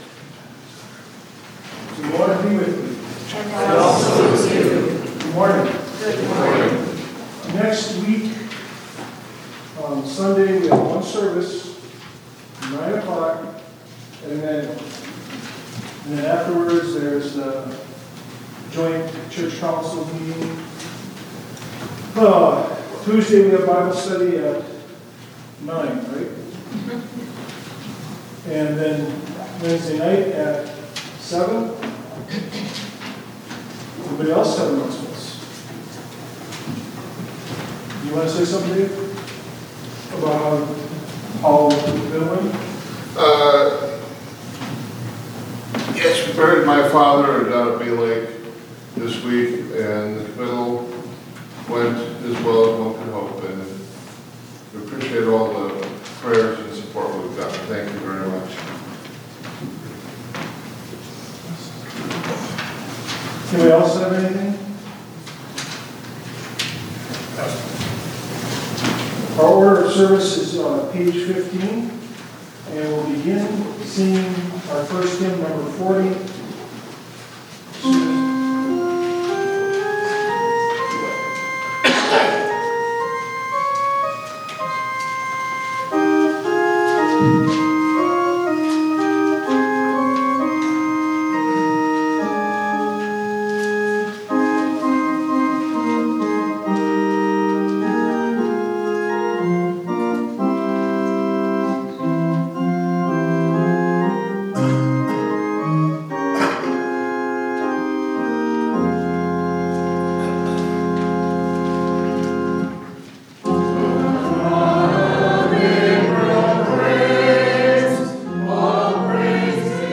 Zion Worship 22 Jun 25